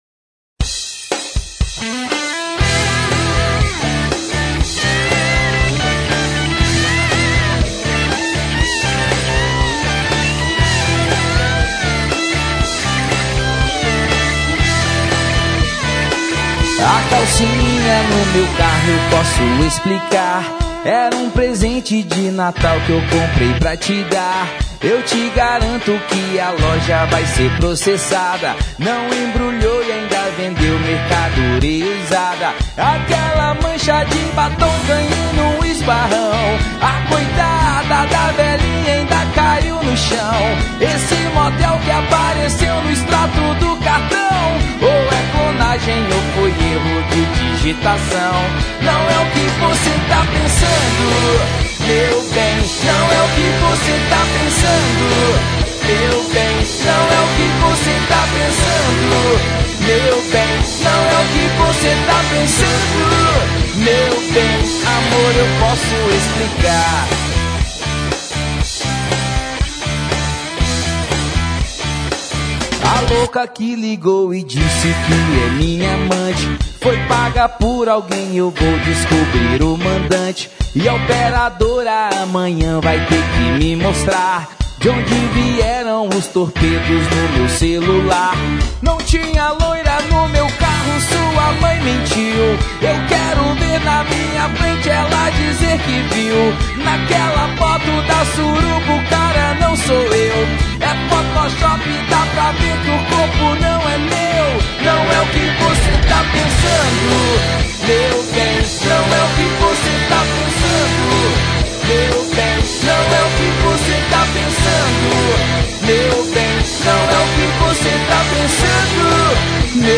baixo
guitarra base
bateria